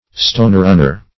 Search Result for " stonerunner" : The Collaborative International Dictionary of English v.0.48: Stonerunner \Stone"run`ner\, n. (Zool.) (a) The ring plover, or the ringed dotterel.